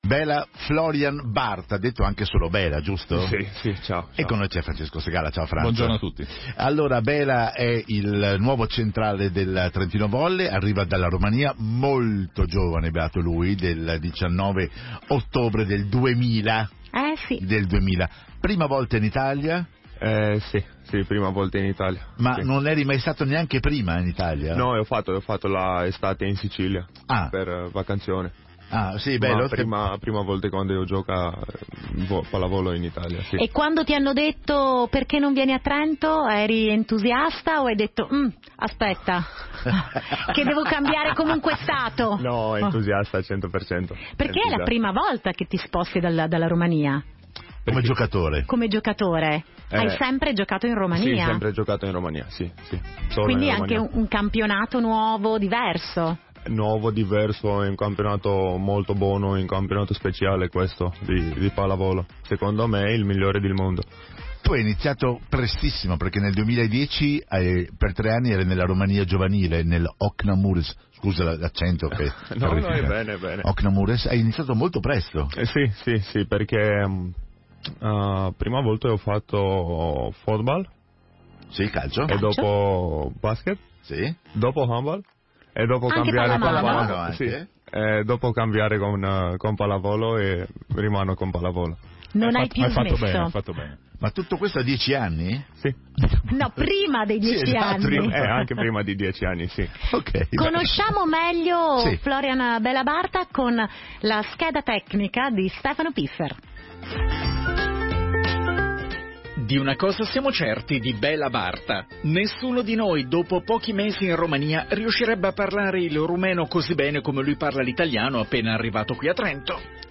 on air on Radio Dolomiti